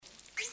Dolphin Bang Sound Effect Free Download
Dolphin Bang